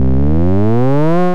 • 1. ピッチ・スライド・アップ[01xx]・・・
サンプルのピッチが、ライン01〜07まで28（16進法）の割合で上がり続けます